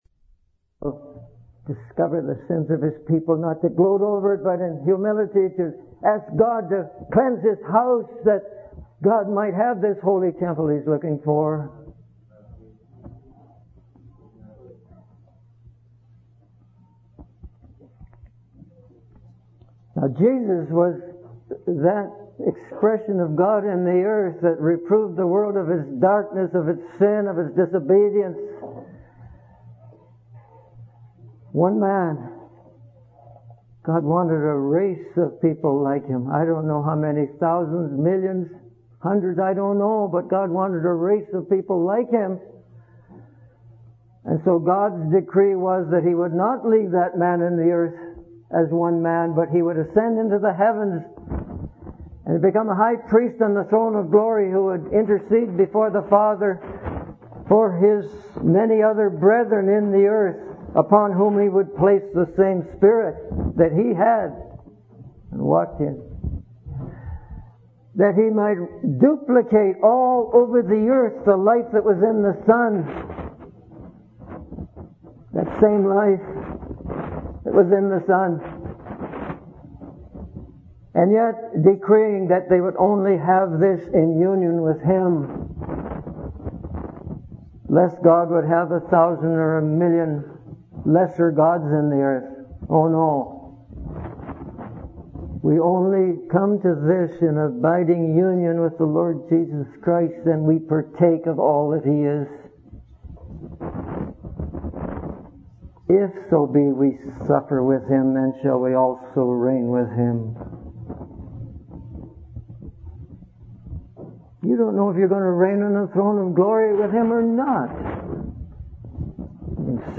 In this sermon, the speaker emphasizes the importance of Jesus being the central focus in our meetings and gatherings. He encourages believers to come to meetings not for performance or entertainment, but to truly encounter and serve Jesus.